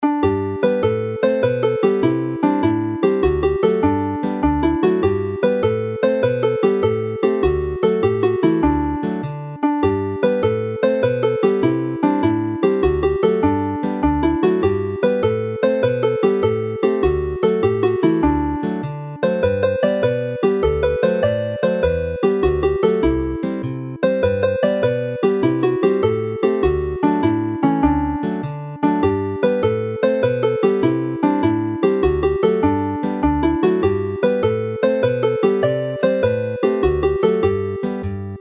The jig Siop y Pentre (the village shop) is developed from the melody of Tŷ Fy Nhad.